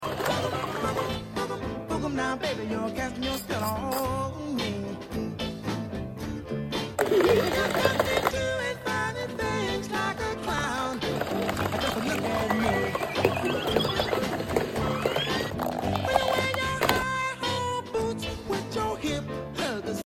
Balls pop, roll, and tumble around while fun tunes play.